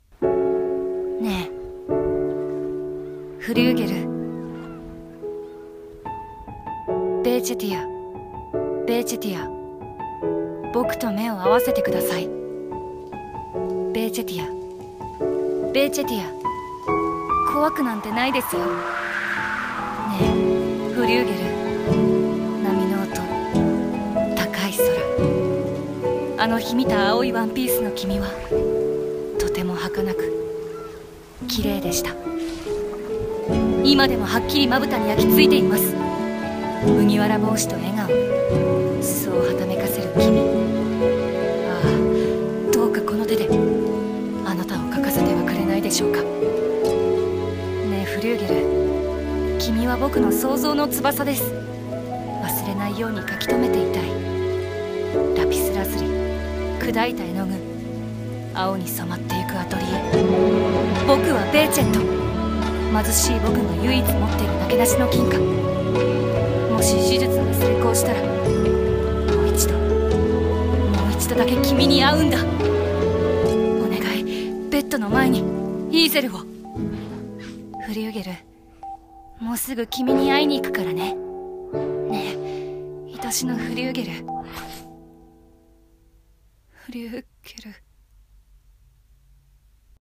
CM風声劇「ねぇ、フリューゲル」お手本